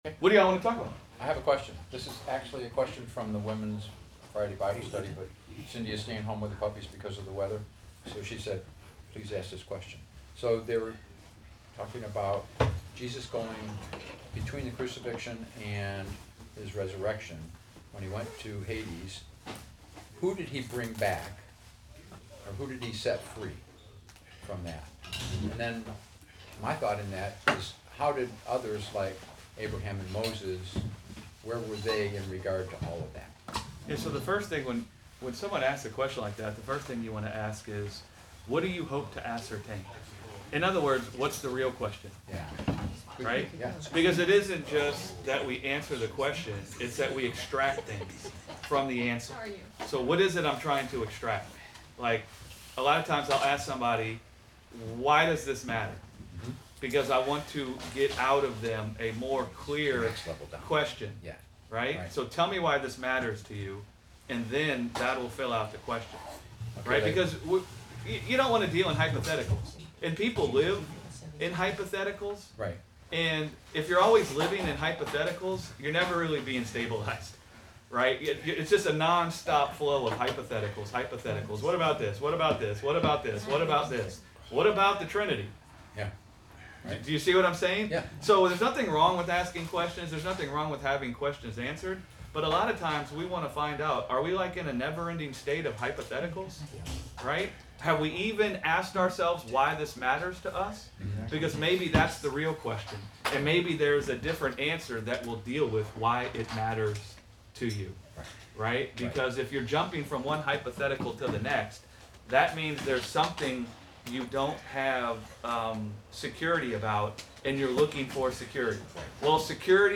Sunday Bible Study: Who Came Out of Hades with Jesus - Gospel Revolution Church